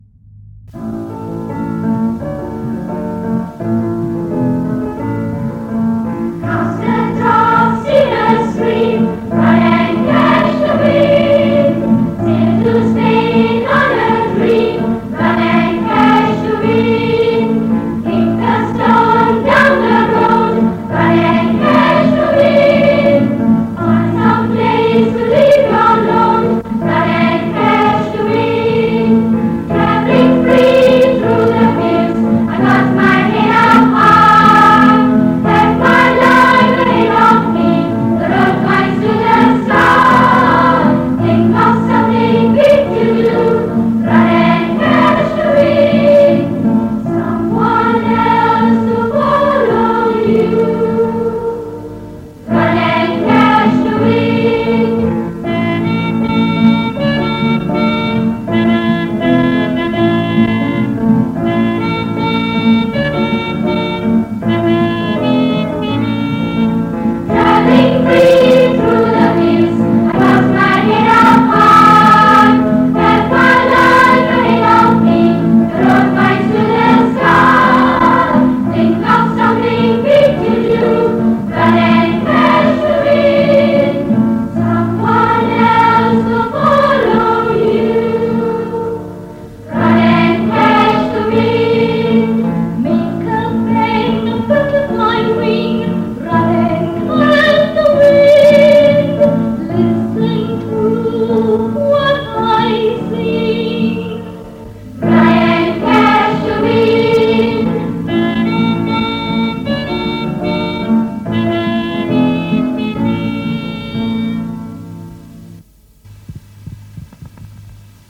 Location : Ipoh
This part features more songs from the MGS Singout of 1968.